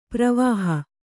♪ pravāha